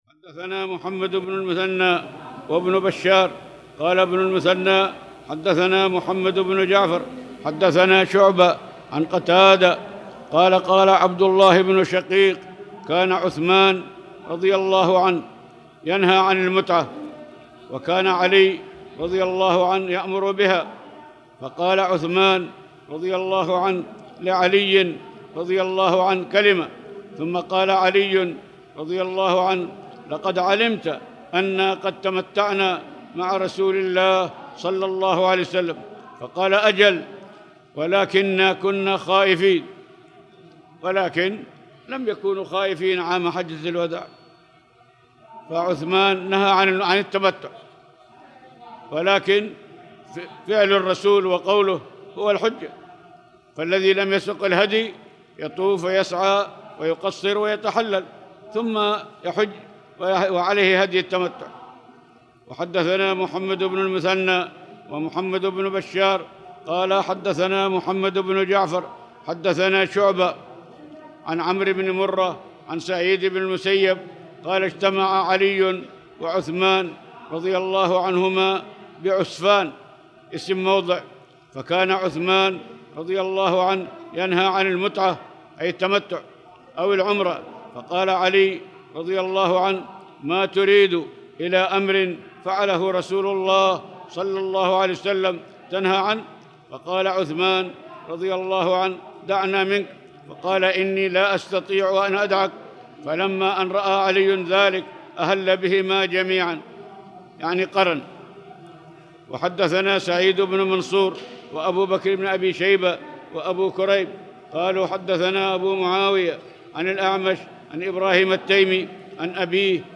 تاريخ النشر ٢ محرم ١٤٣٨ هـ المكان: المسجد الحرام الشيخ